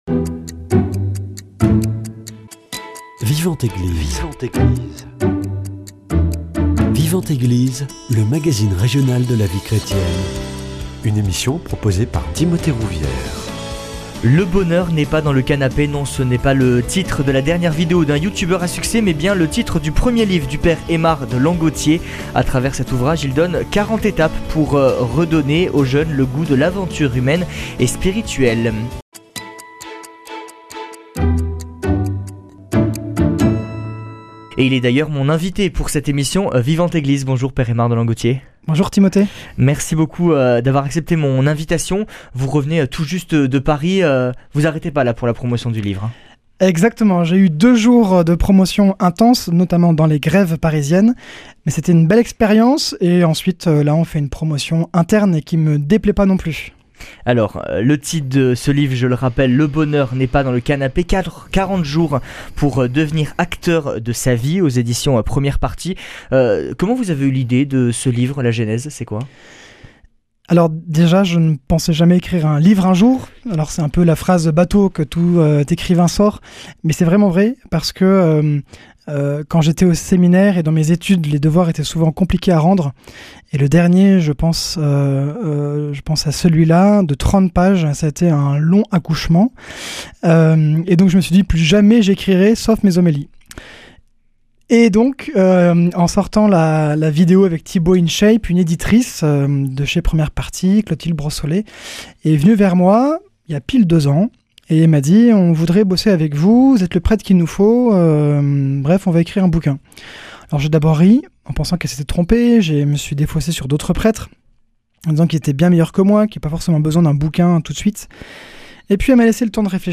A travers 40 défis, il invite les jeunes à sortir de leur zone de confort et à goûter à l’aventure humaine et spirituelle. Il est d’ailleurs mon invité pour cette émission Vivante Eglise.